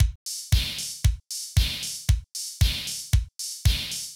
MOO Beat - Mix 7.wav